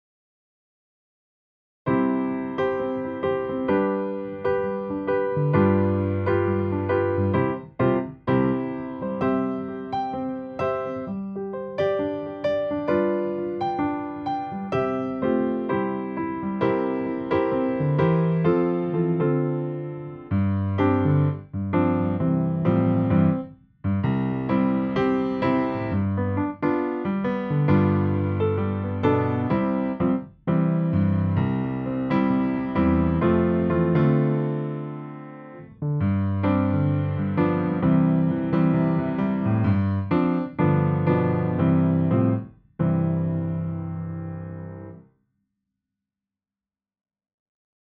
Backing -